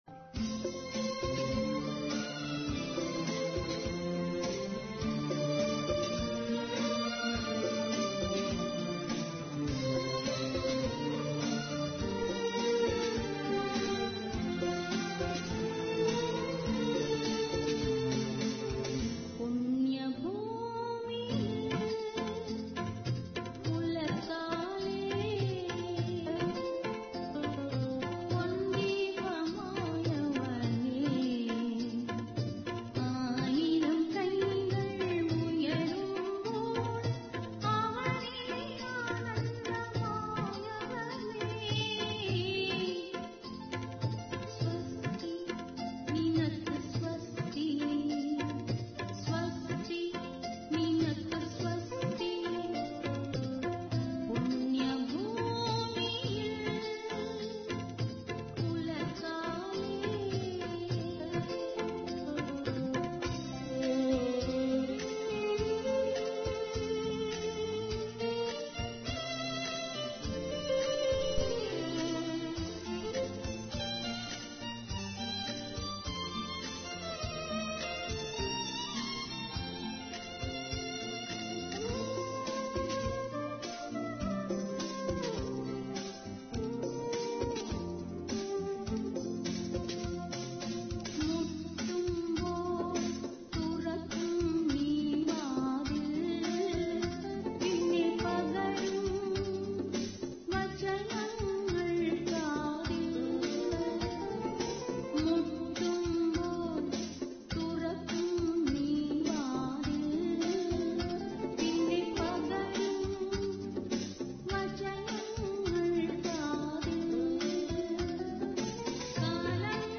Malayalam Devotionals
50 christian